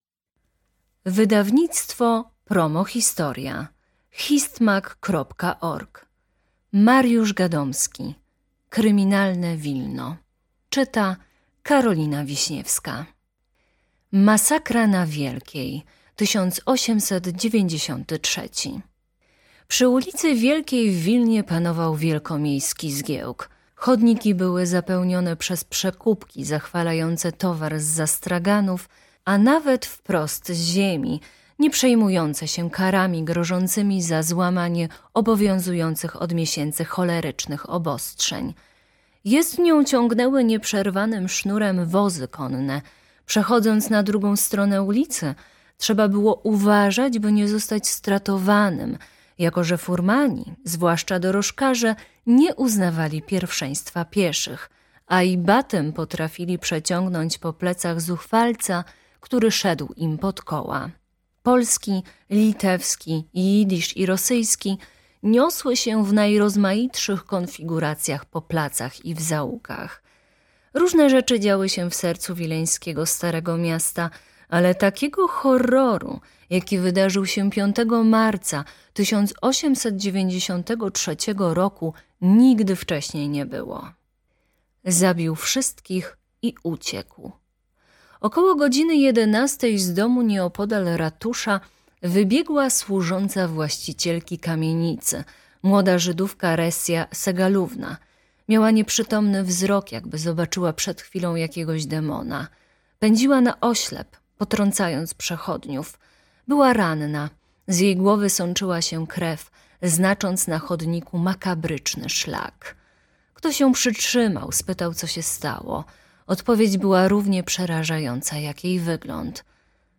Posłuchaj fragmentu książki: MP3